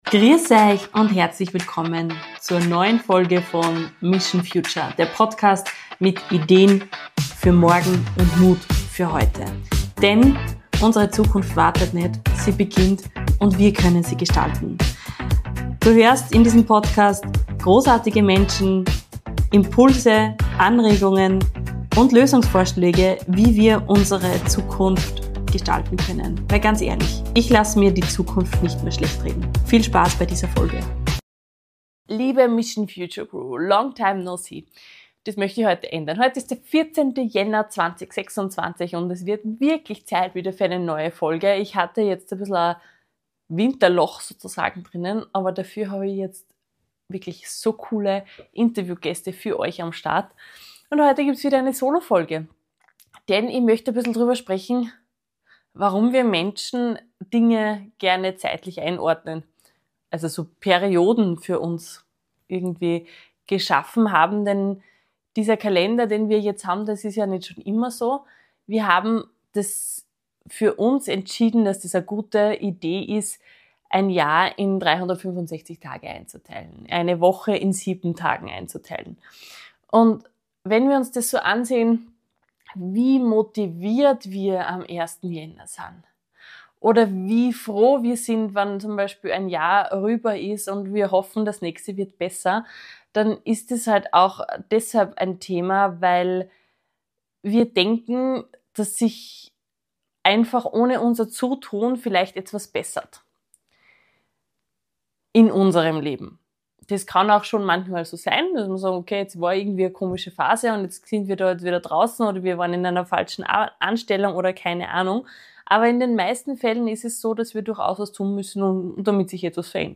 In dieser Solo-Folge von Mission Future spreche ich darüber, warum wir Menschen Anfänge lieben, wieso Motivation allein nicht reicht und weshalb echte Veränderung nichts mit Kalenderdaten zu tun hat...